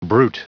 Prononciation du mot brute en anglais (fichier audio)
Prononciation du mot : brute